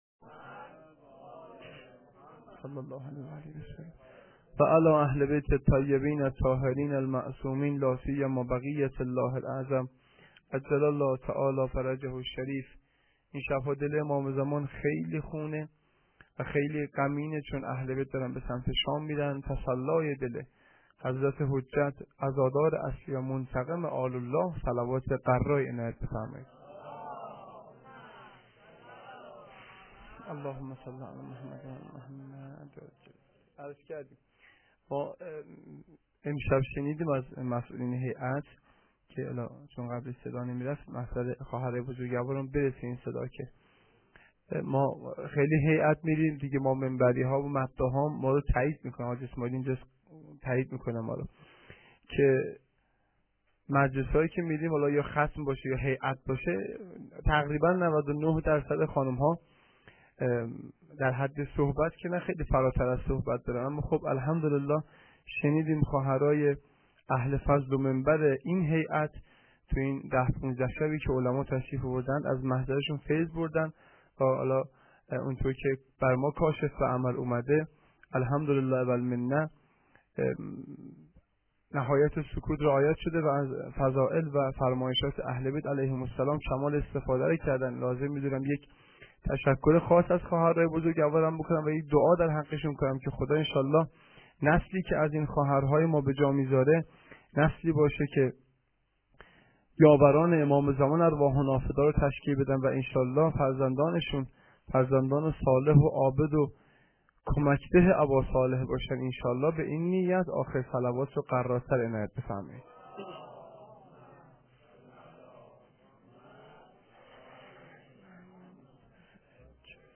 sokhanrani14.lite.lite.mp3